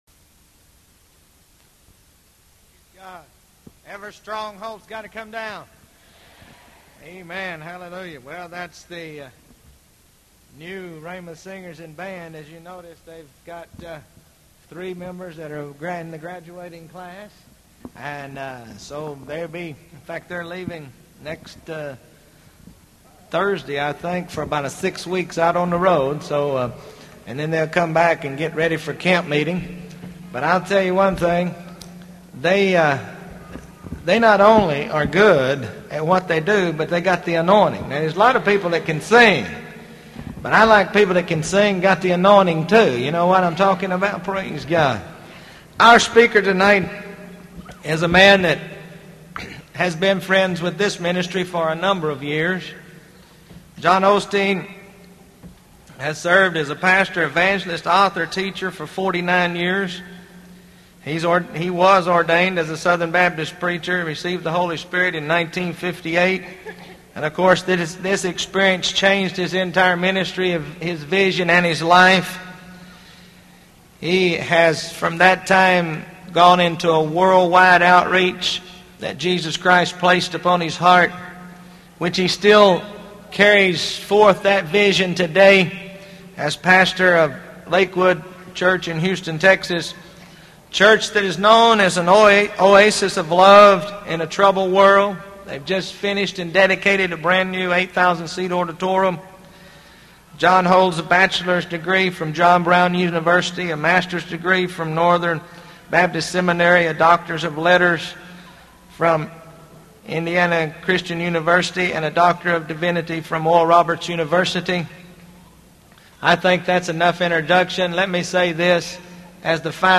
RHEMA-John Osteen-1988-Graduation-Service.mp3